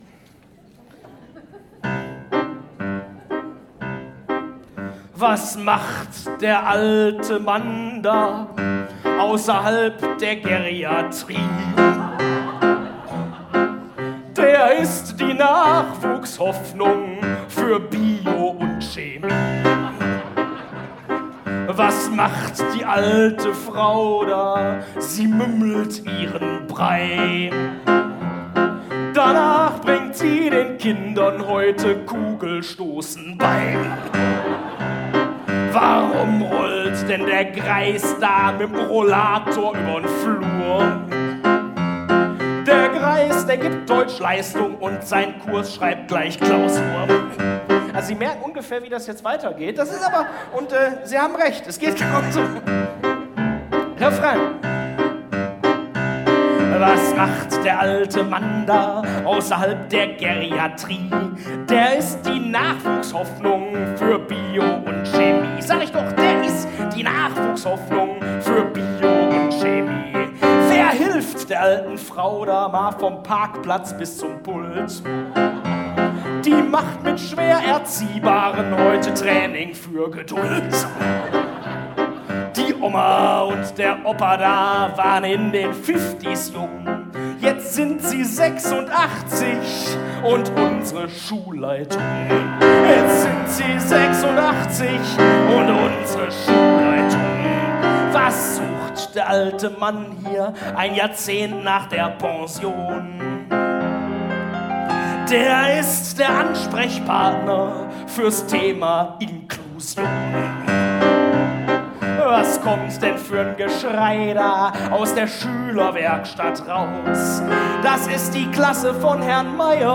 aufgenommen am 1.3. im Ebertbad Oberhausen